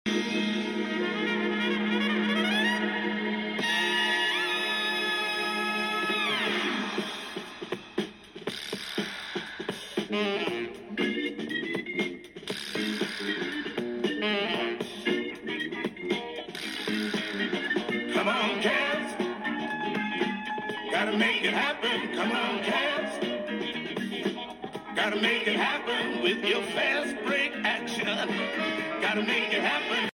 The thrill of the hunt and a funky tune too with a potential for a $32 profit!